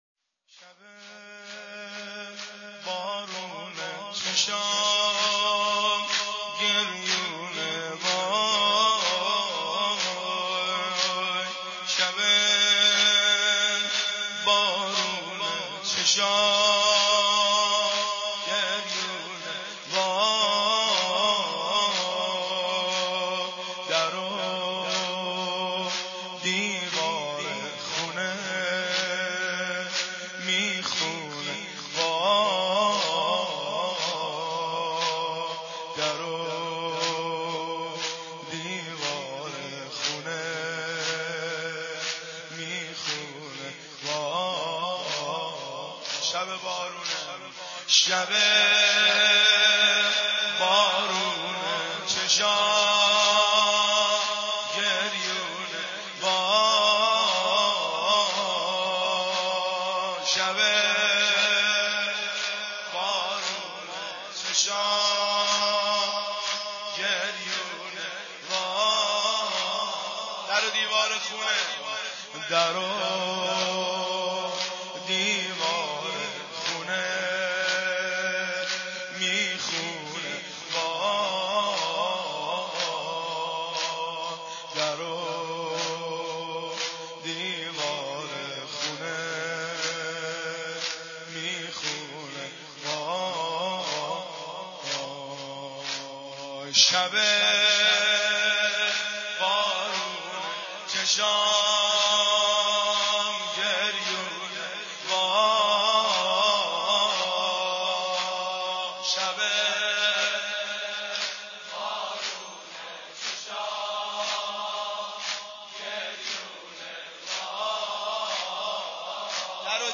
• هیئت فاطمة الزهرا آستانه اشرفیه - شام شهادت حضرت زهرا 1390